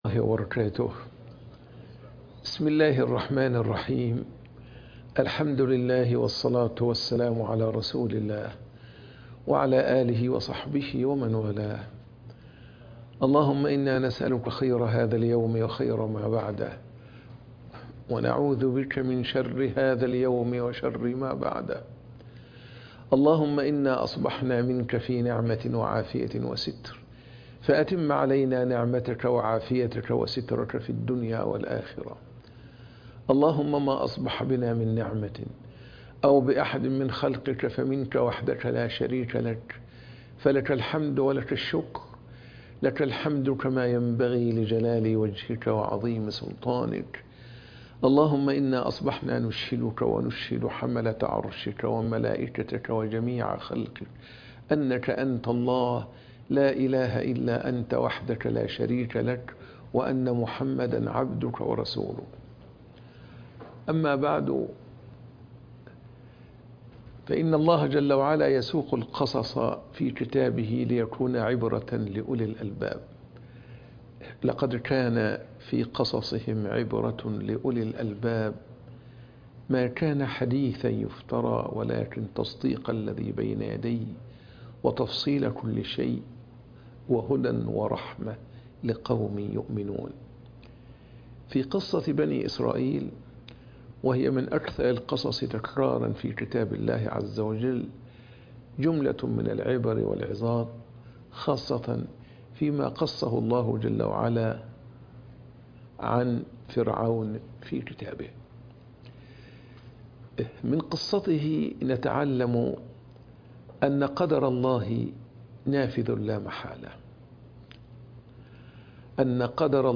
عبر من قصة فرعون - درس بعد الفجر